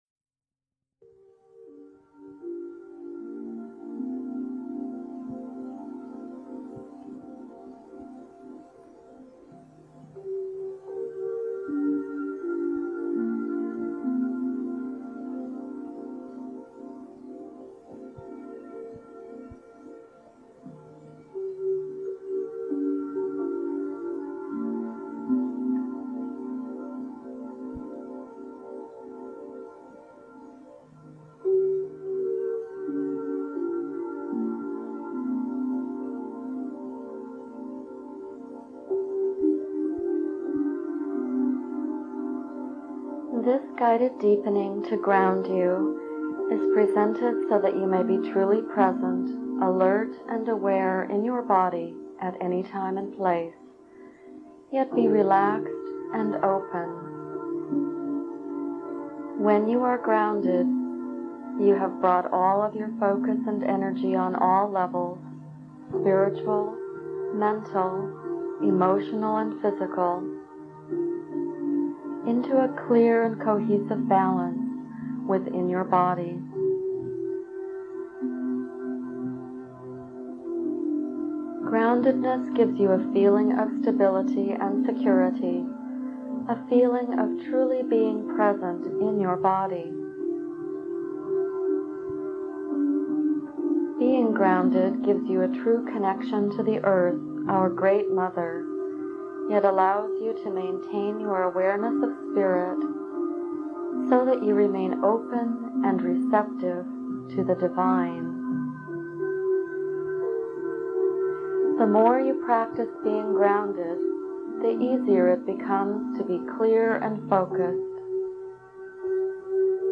Grounding Meditation 1995